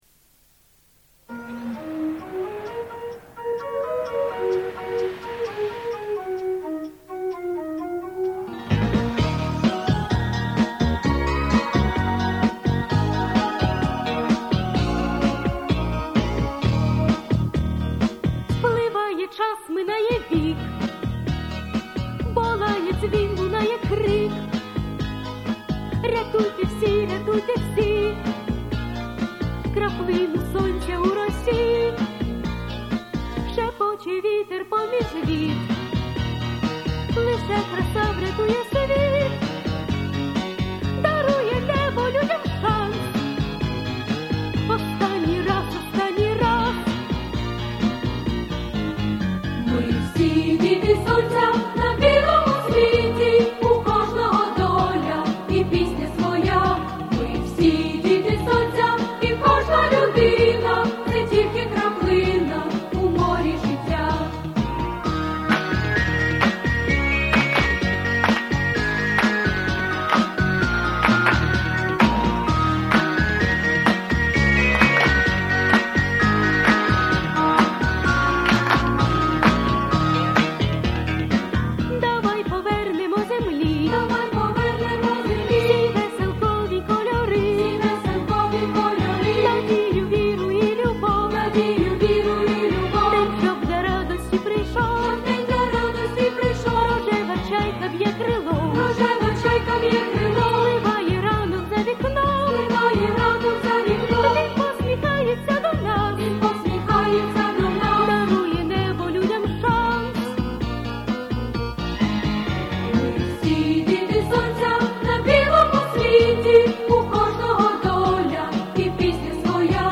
Помірна
Соло
Дитяча
Дитячі.
Плюсовий запис